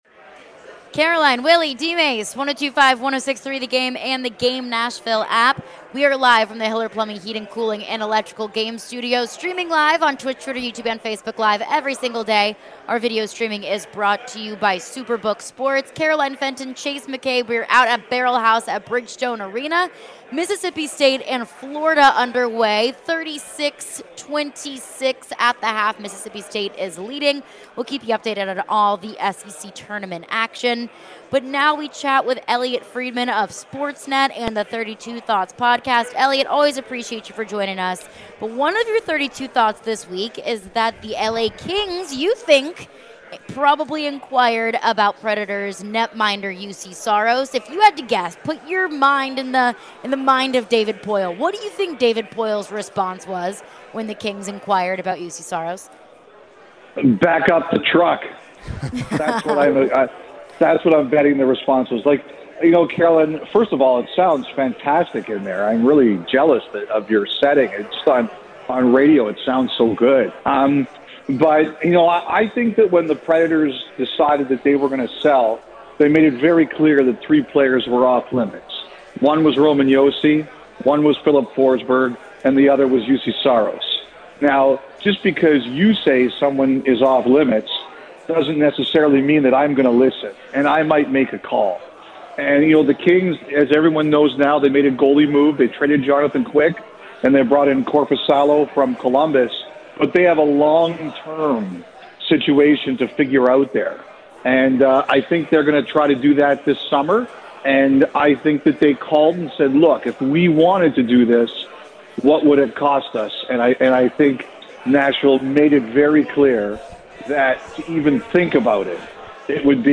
Elliotte Friedman Interview (3-9-23)